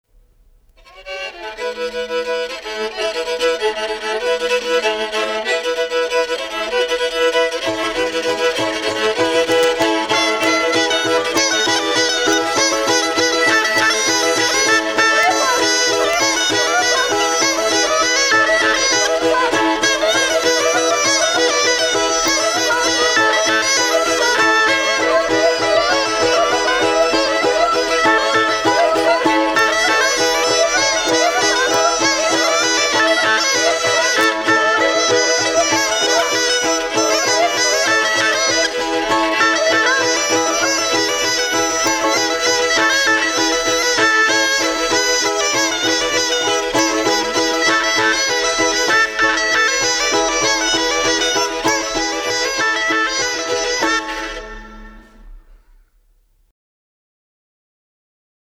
Голоса уходящего века (Курское село Илёк) Заяц (скрипка, балалайка, рожок, кукиклы, инструментальный наигрыш)
23_Наигрыш.mp3